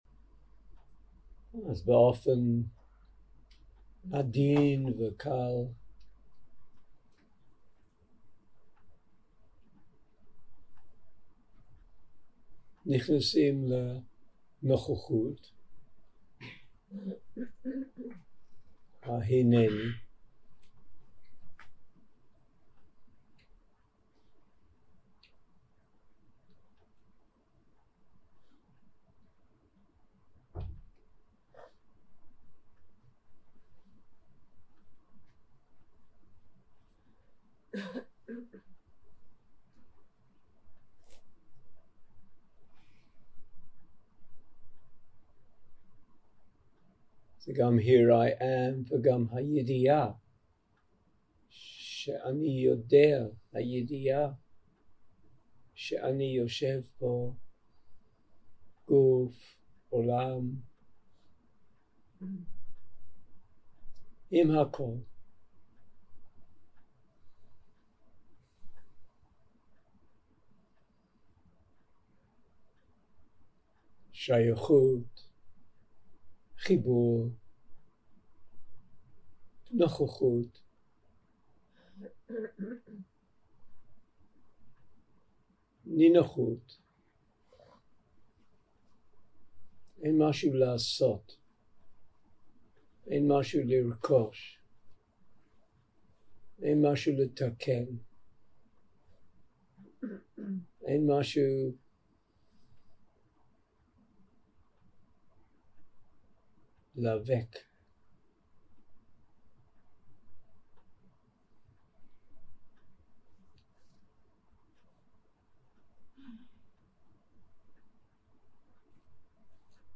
סוג ההקלטה: מדיטציה מונחית שפת ההקלטה